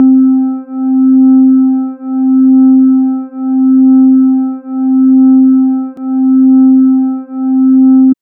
Loop points clicking issue in HISE but not in other samplers